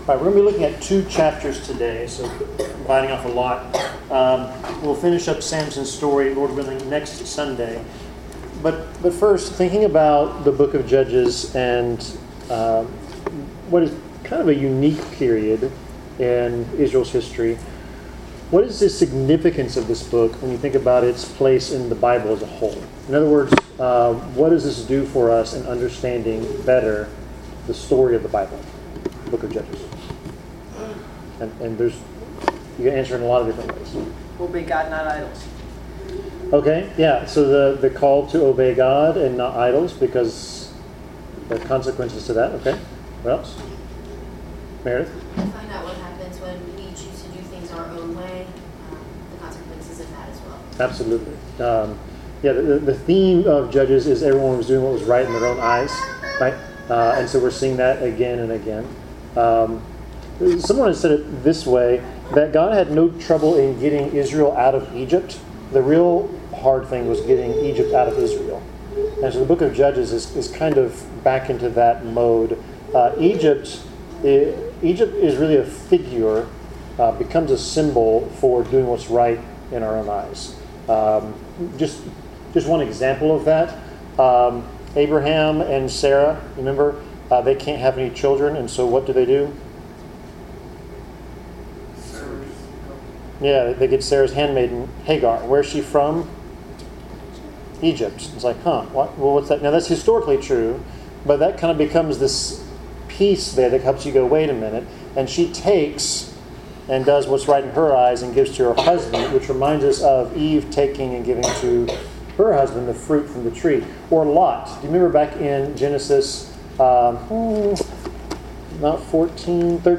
Bible Class: Judges 14-15